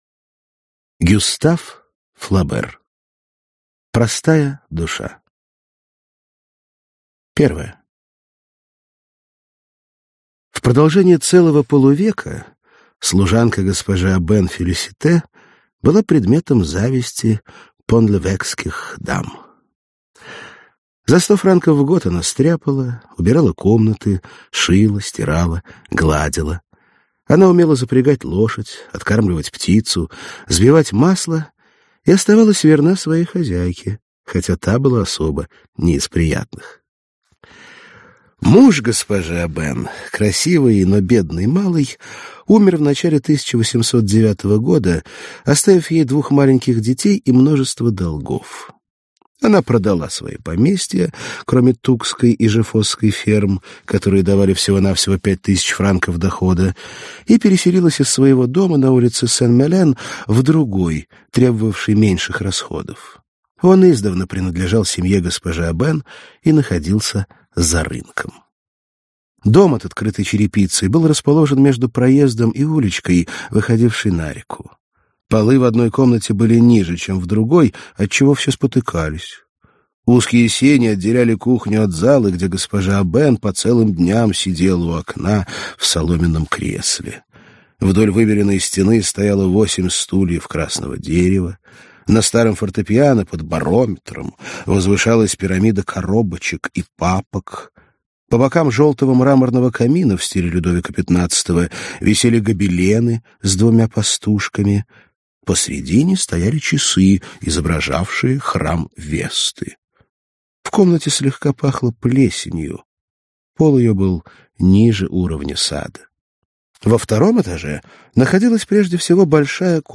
Аудиокнига Простая душа | Библиотека аудиокниг
Прослушать и бесплатно скачать фрагмент аудиокниги